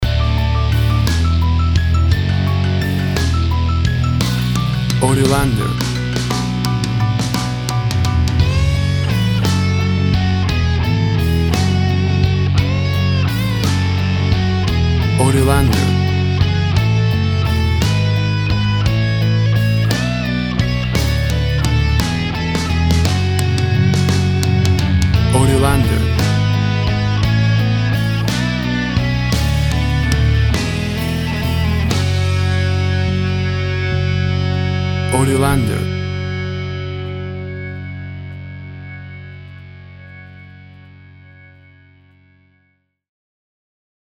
A big and powerful rocking version
Tempo (BPM) 175